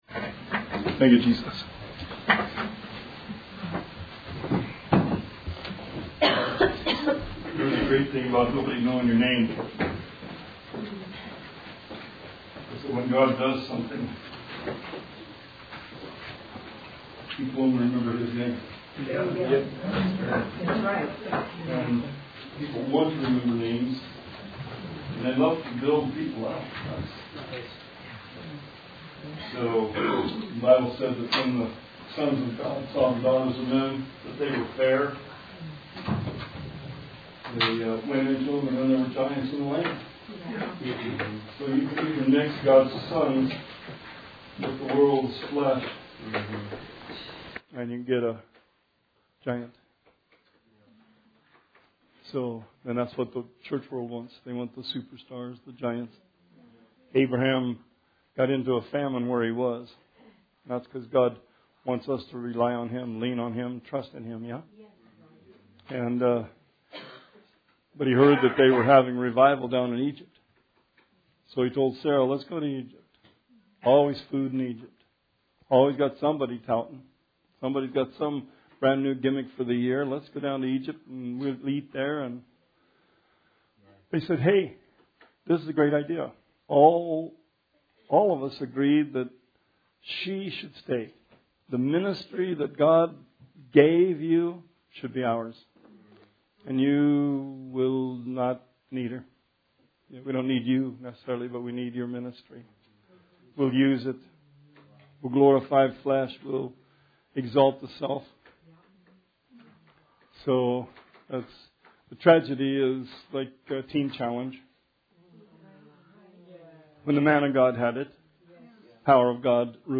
Bible Study 11/27/19